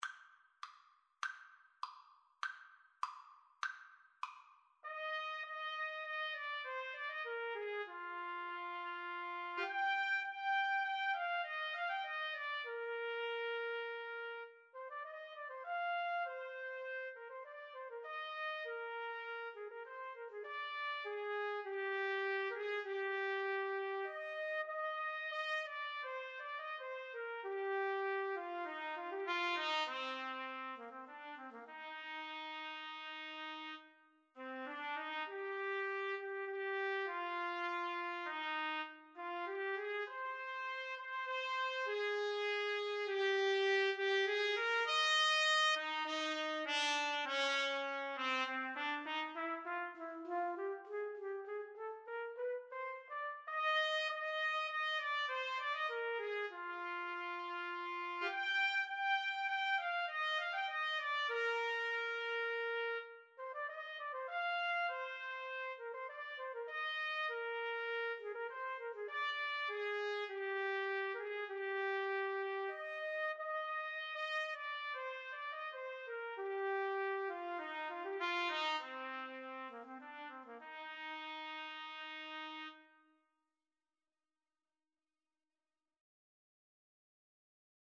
2/4 (View more 2/4 Music)
Allegretto
Trumpet Duet  (View more Intermediate Trumpet Duet Music)
Classical (View more Classical Trumpet Duet Music)